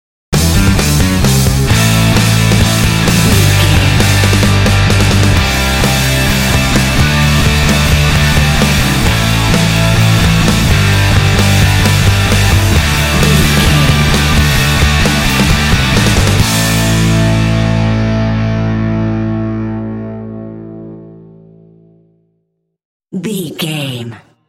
Epic / Action
Aeolian/Minor
powerful
energetic
heavy
electric guitar
drums
bass guitar
heavy metal
classic rock